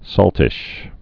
(sôltĭsh)